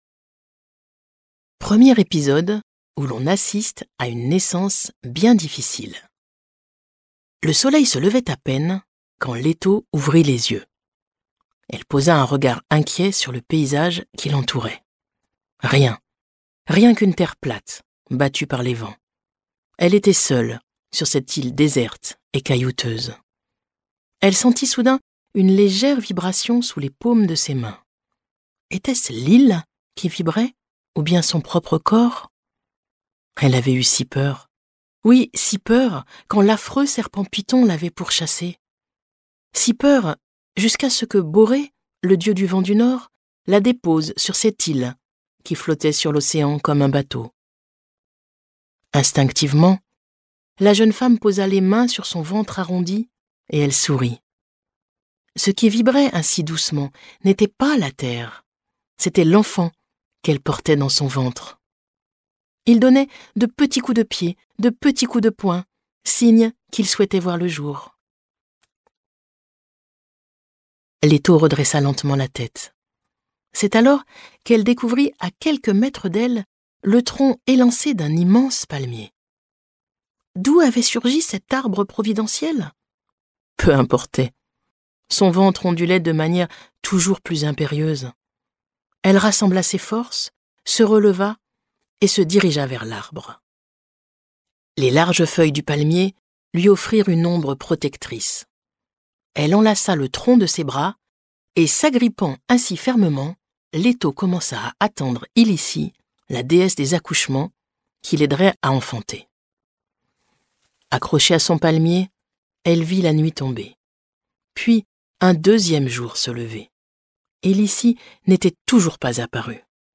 Diffusion distribution ebook et livre audio - Catalogue livres numériques
Quatrième volet de La mythologie grecque en cent épisodes, cette histoire renoue avec l'oralité des premiers récits.